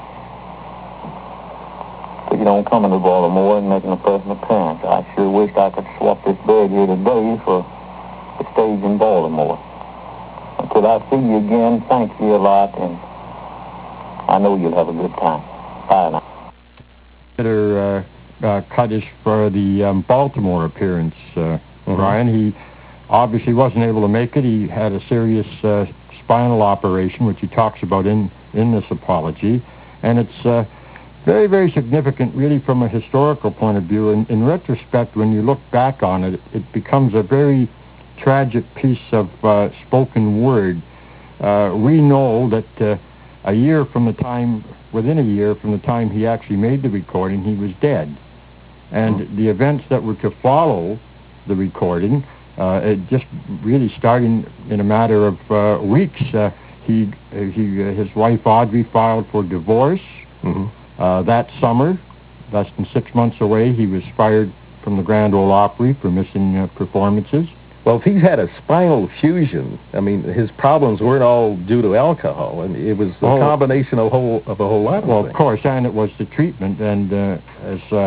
[Hank's apology speech recorded early 1952] ~ [Ray Price talks about Hank's Spinal Surgery]
containing the apology speech, recorded by Hank Williams in early 1952